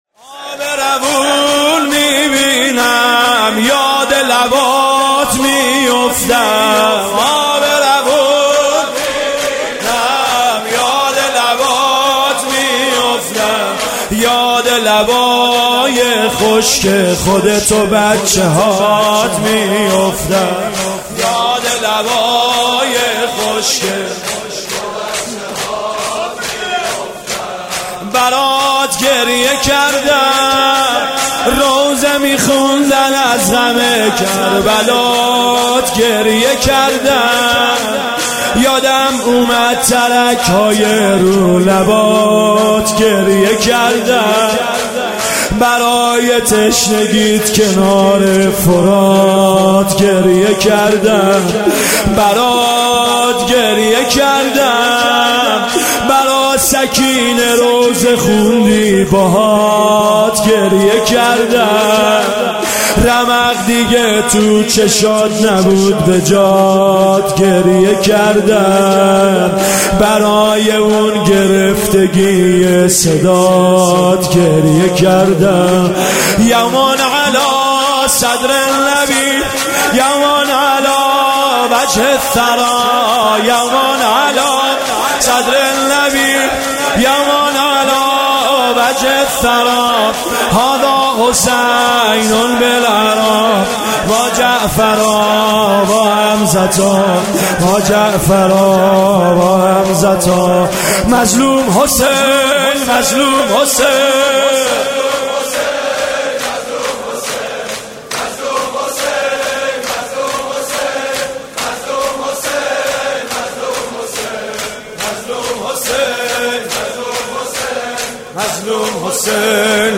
جلسه هفتگی
music-icon شور